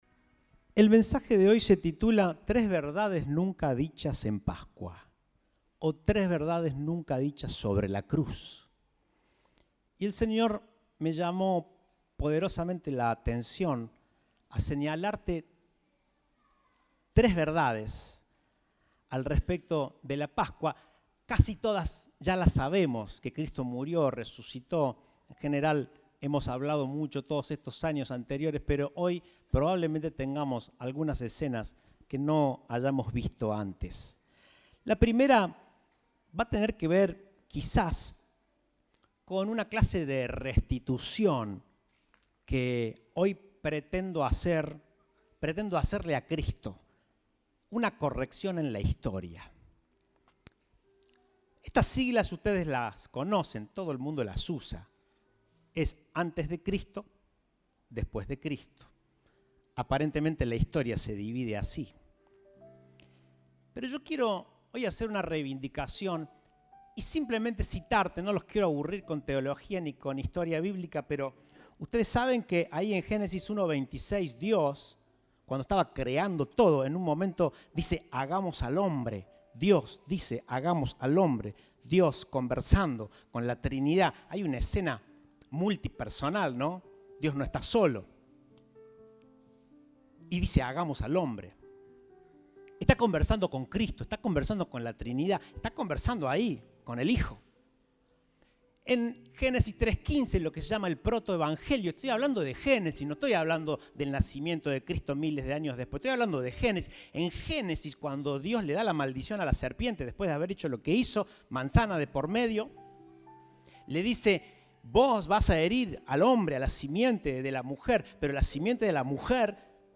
Compartimos el mensaje del Domingo 9 de Abril de 2023